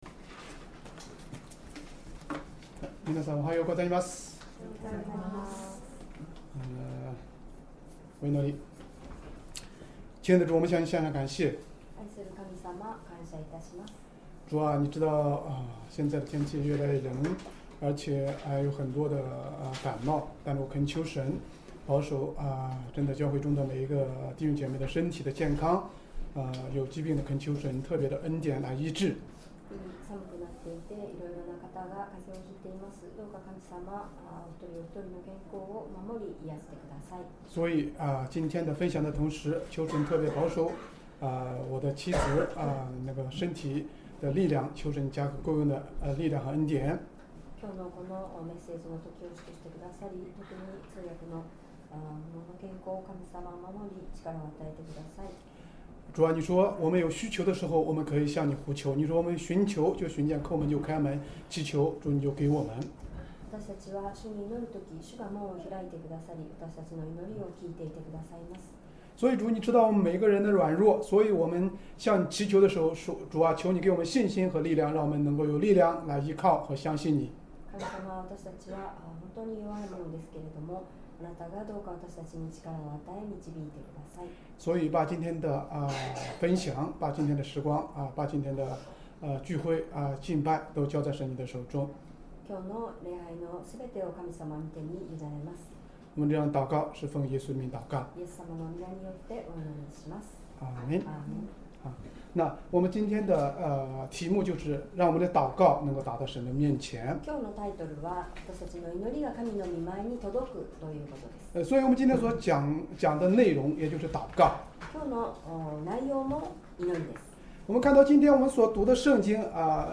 Sermon
2025年12月7日 主日礼拝 説教 「祈りが神の前に届く」 聖書 使徒の働き 10章 1-8節 10:1 さて、カイサリアにコルネリウスという名の人がいた。